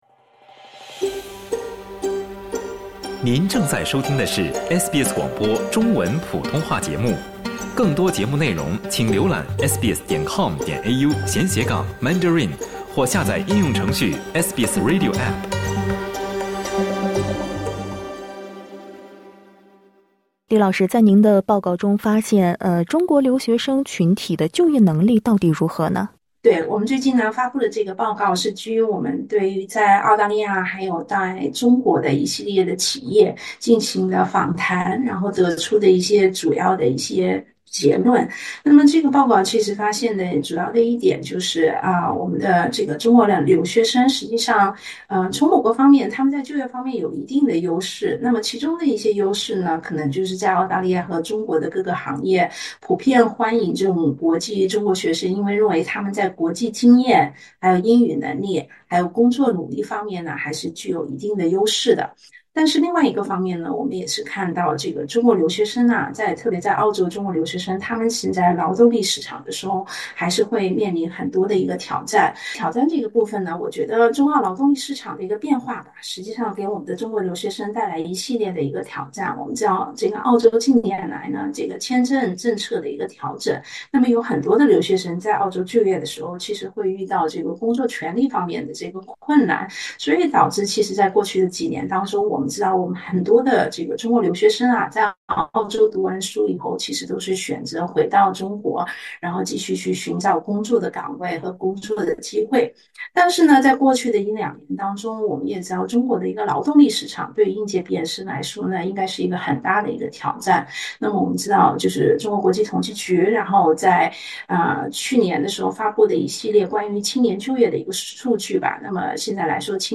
请点击收听完整采访： LISTEN TO “就业能力不达预期”：报告揭中国留学生在中澳找工的双重挑战 SBS Chinese 14:18 cmn 欢迎下载应用程序SBS Audio，订阅Mandarin。